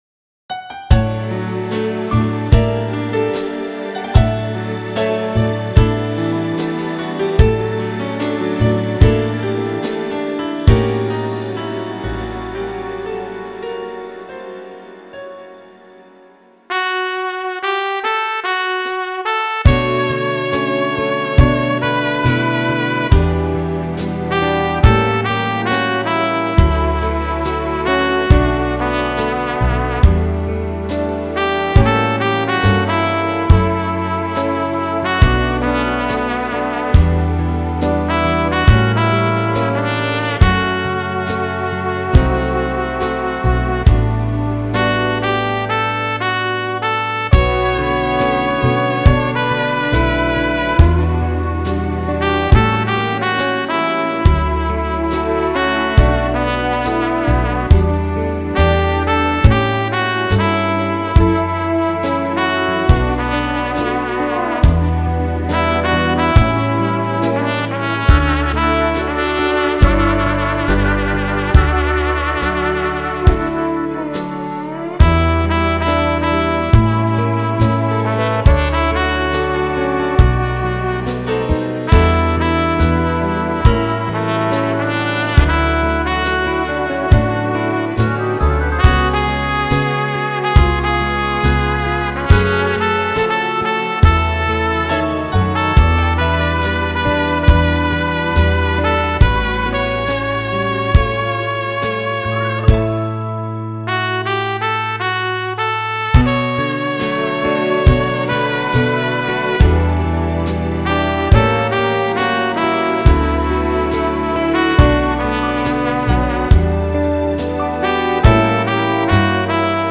The “lush” part was easy… remember?  Lots of violins.
Oh, and sprinkle a little piano in there… also pretty.
No problem on my keyboard – just push a button.